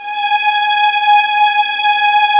Index of /90_sSampleCDs/Roland LCDP13 String Sections/STR_Violins III/STR_Vls6 f wh%
STR  VLS F09.wav